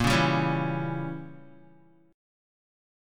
A#dim7 chord